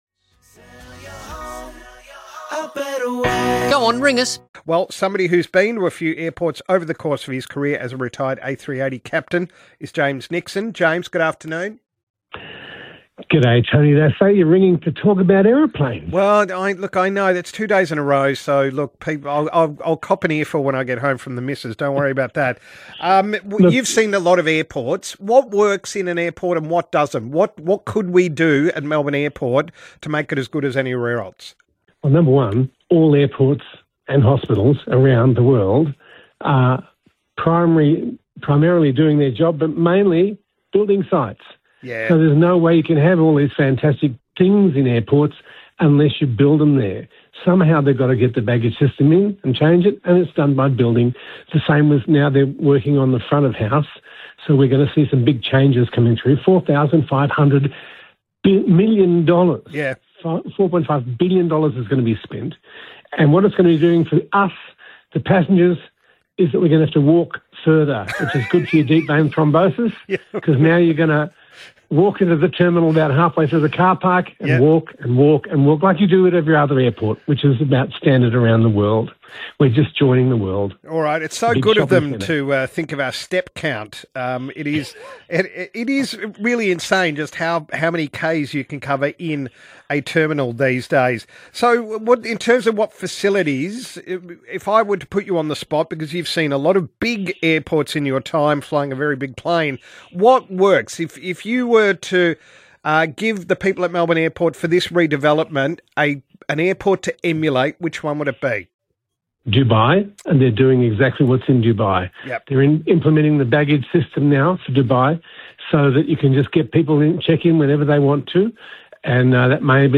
Radio Archive